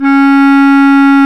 Index of /90_sSampleCDs/Roland LCDP04 Orchestral Winds/CMB_Wind Sects 1/CMB_Wind Sect 3
WND BSCLAR08.wav